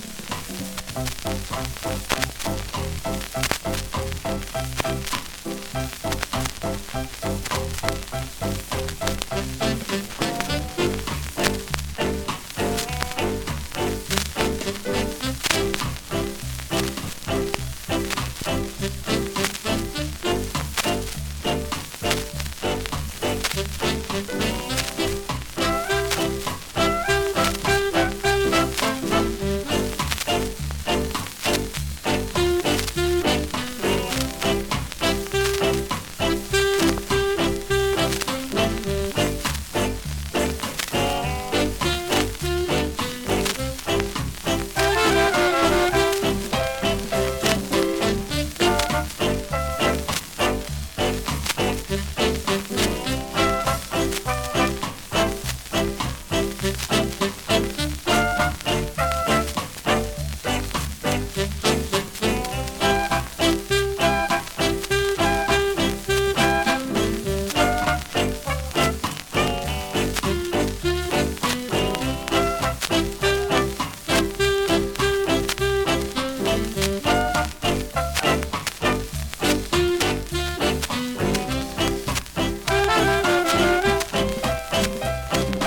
※ジリジリ有
スリキズ、ノイズそこそこありますが